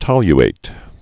(tŏly-āt)